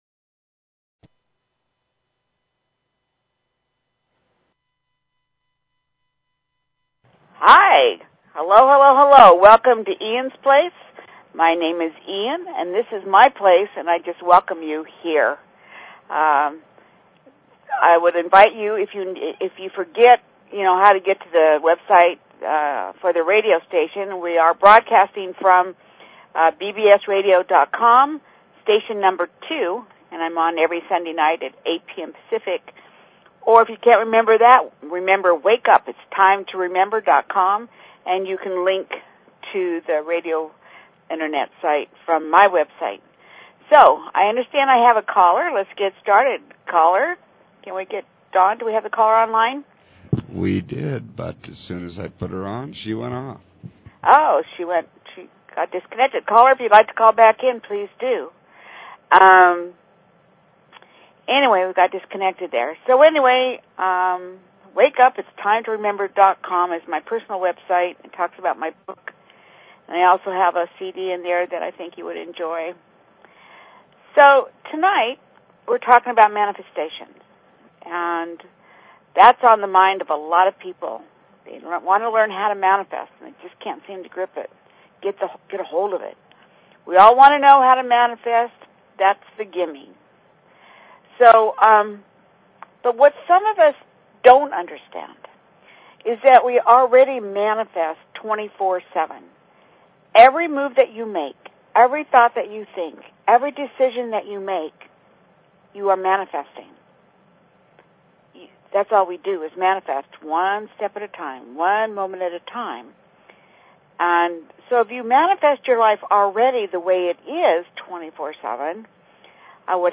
Talk Show Episode, Audio Podcast, Eans_Place and Courtesy of BBS Radio on , show guests , about , categorized as